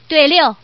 Index of /guizhou_ceshi_0/update/11362/res/sfx/ddz/woman/